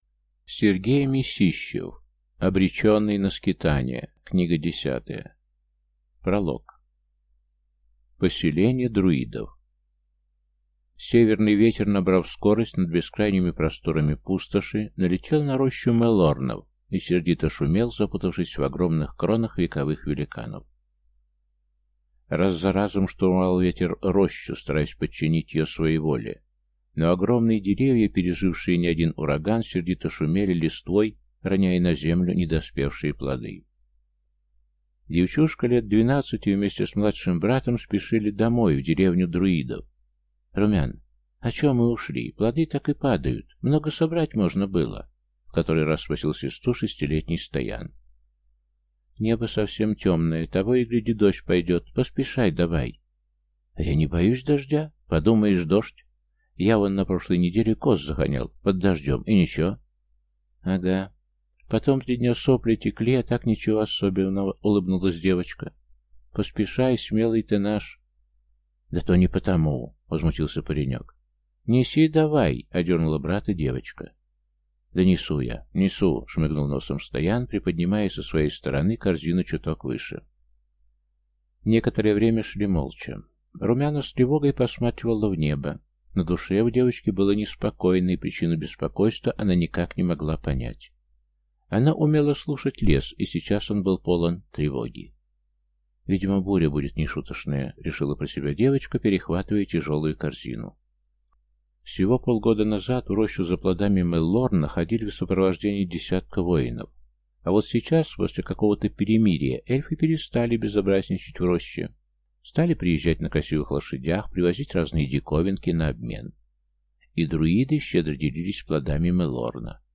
Аудиокнига Обреченный на скитания. Книга 10 | Библиотека аудиокниг